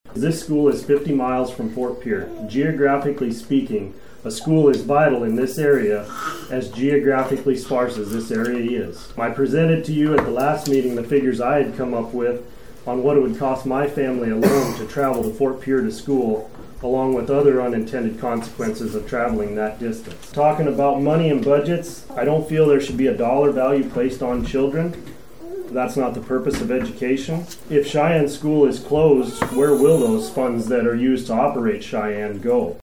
During the public comment portion, about a dozen residents– all of them opposed– addressed the possibility of closing the school.